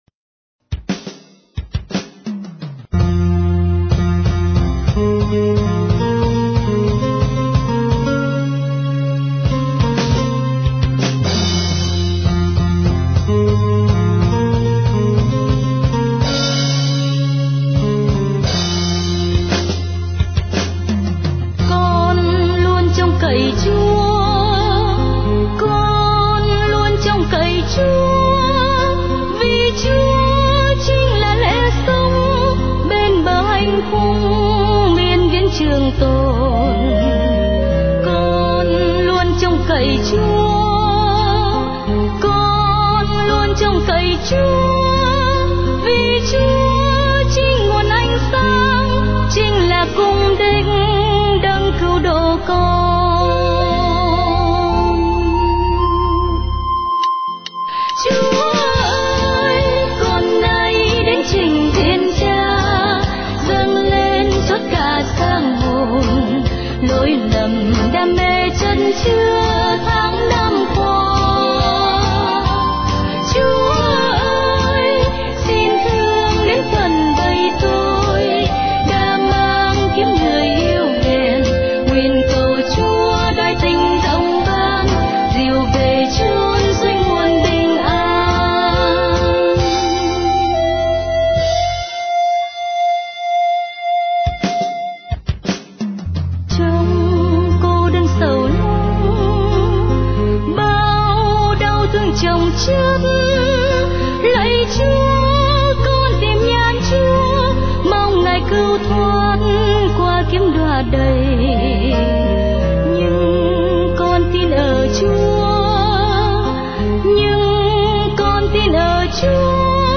Dòng nhạc : Cầu hồn